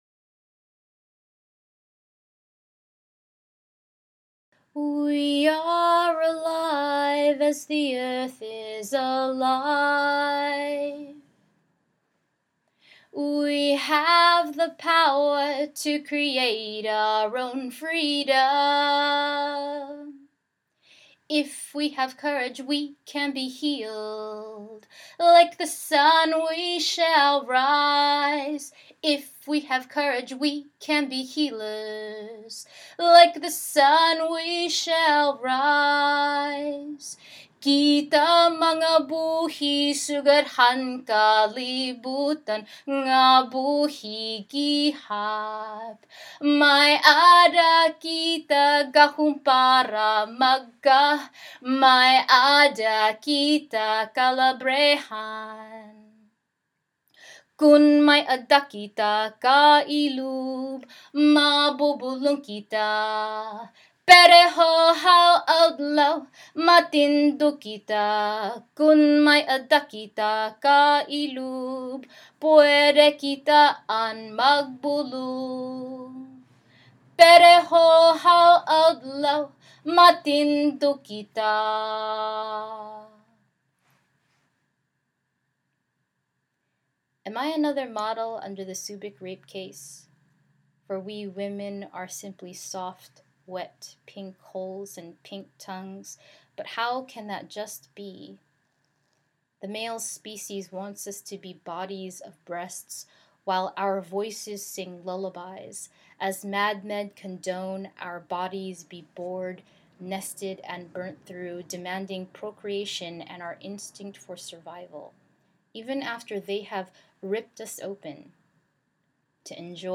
This poem was originally performed at the Thirteenth Artists Against Rape: In Remembering, We Rise in 2010.